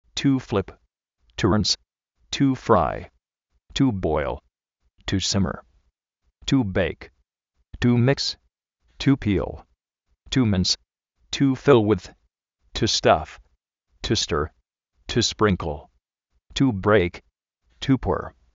ríns
símer
fil uíz
sprínkl